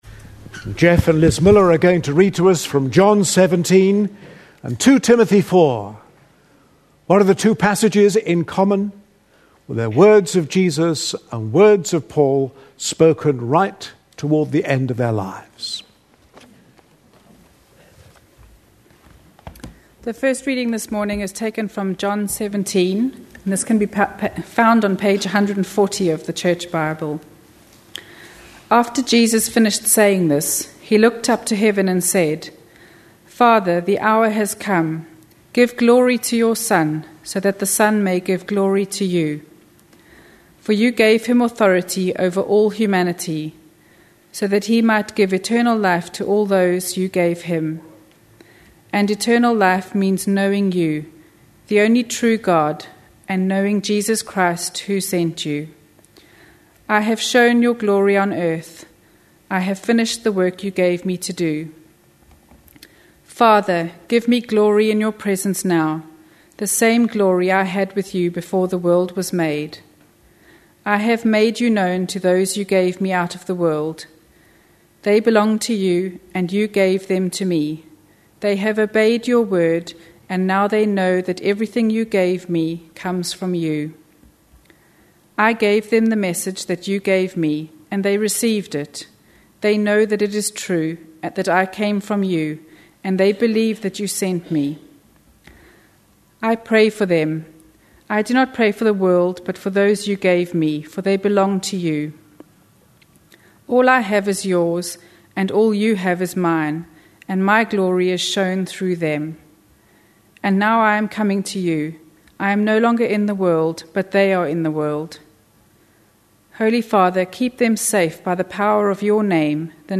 A sermon preached on 27th March, 2011, as part of our A Passion For.... series.
(Momentary interference from mobile telephone on recording.)